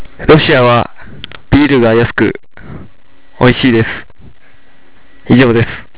研修生の声　３